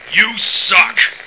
flak_m/sounds/male1/int/M1yousuck.ogg at 098bc1613e970468fc792e3520a46848f7adde96
M1yousuck.ogg